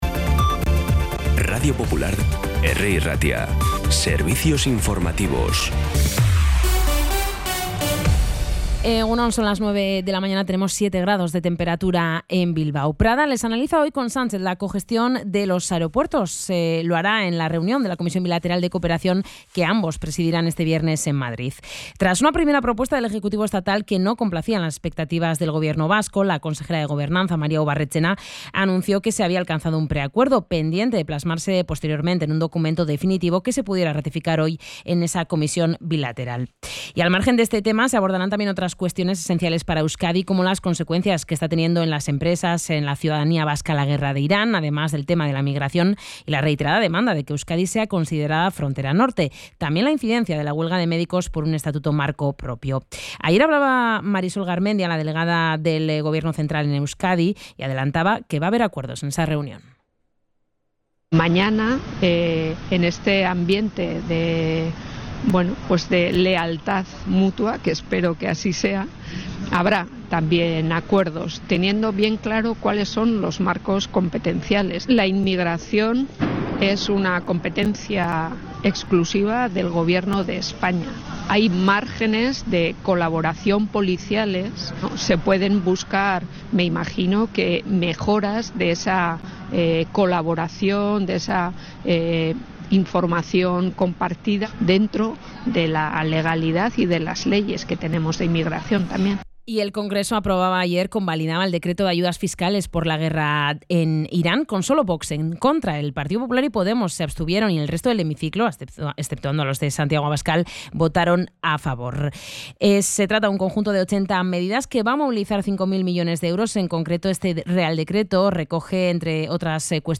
Las noticias de Bilbao y Bizkaia de las 9 , hoy 27 de marzo
Los titulares actualizados con las voces del día. Bilbao, Bizkaia, comarcas, política, sociedad, cultura, sucesos, información de servicio público.